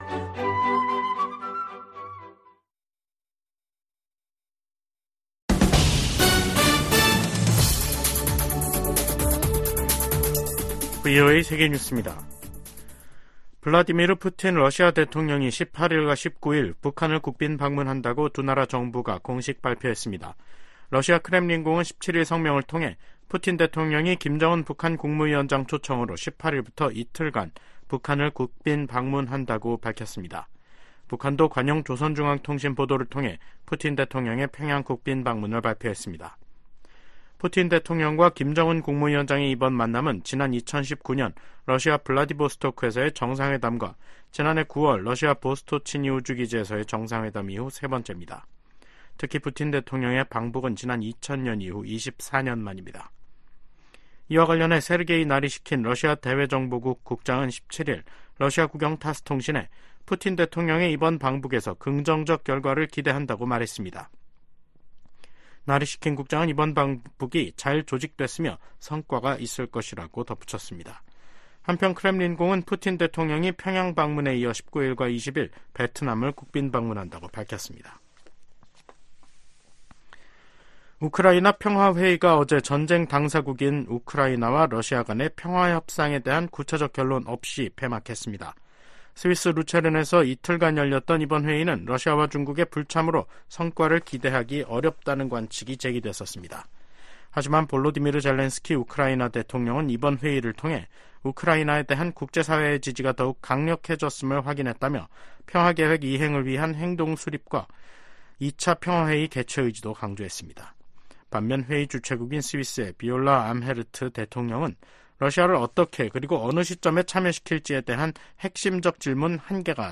VOA 한국어 간판 뉴스 프로그램 '뉴스 투데이', 2024년 6월 17일 2부 방송입니다. 미국,영국,프랑스 등 주요 7개국, G7 정상들이 북한과 러시아간 군사협력 증가를 규탄했습니다. 미국 하원이 주한미군을 현 수준으로 유지해야 한다는 내용도 들어있는 새 회계연도 국방수권법안을 처리했습니다. 북한의 대러시아 무기 지원으로 우크라이나 국민의 고통이 장기화하고 있다고 유엔 주재 미국 차석대사가 비판했습니다.